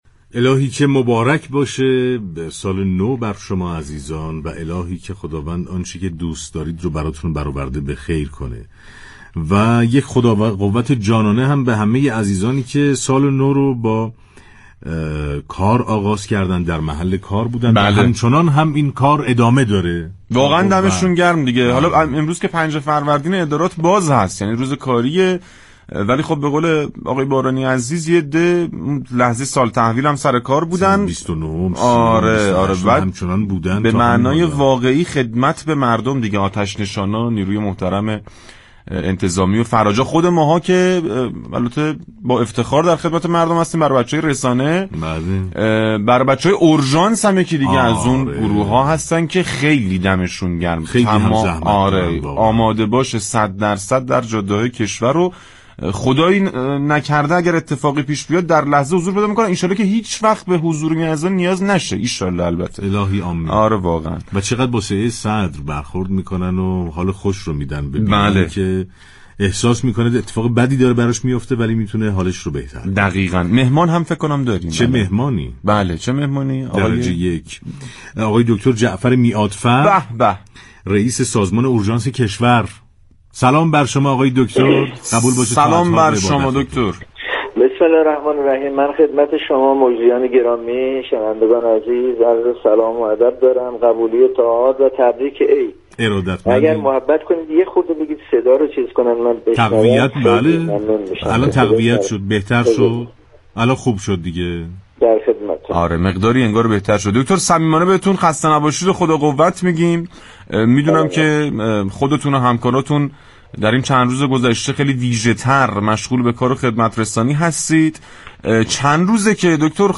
رئیس سازمان اورژانس كشور در گفت و گو با رادیو تهران با بیان اینكه خرید آمبولانس‌های جدید و استخدام نیروهای تازه نفس، اولویت اورژانس كشور در سال 1404 است گفت: خردادماه امسال؛ 5500 نیروی جدید در سازمان اورژانس كشور استخدام می‌شوند.